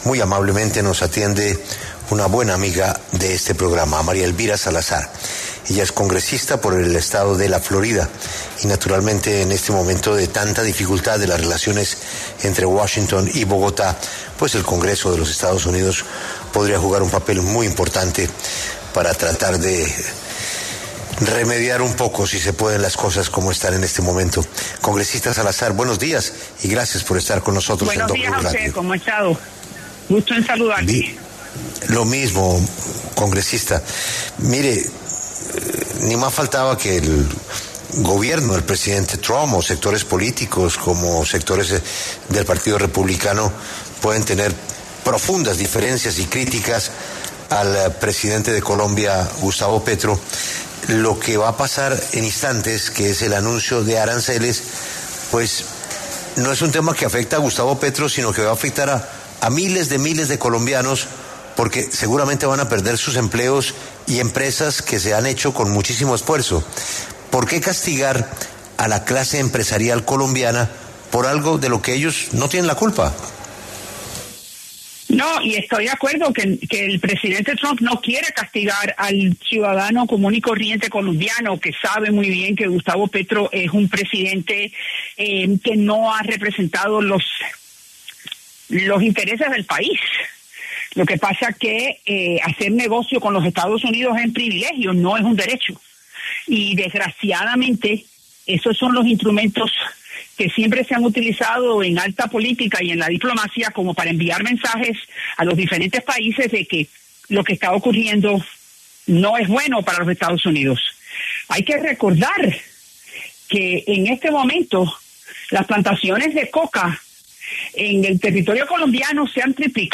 En conversación con La W, María Elvira Salazar, congresista de Estados Unidos, se refirió a la nueva tensión entre Colombia y EE.UU. luego de que el presidente Donald Trump calificara a su homólogo Gustavo Petro como “líder del narcotráfico”.